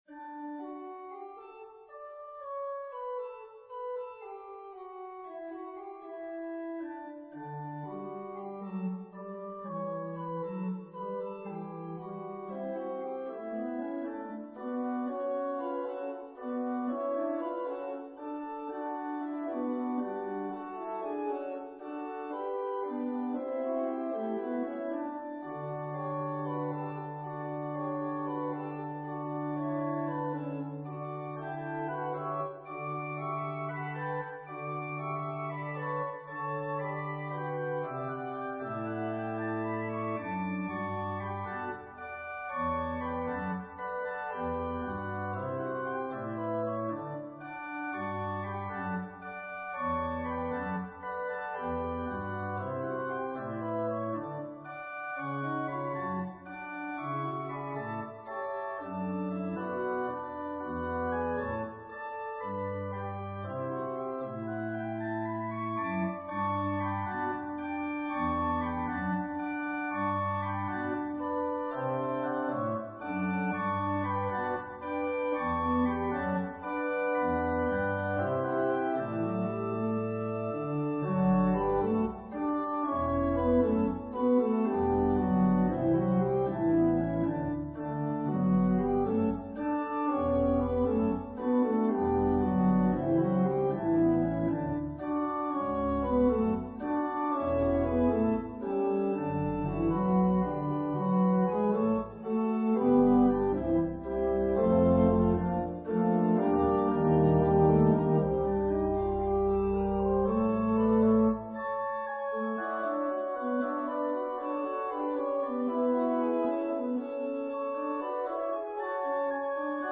An organ solo version
An organist who likes to arrange music for organ.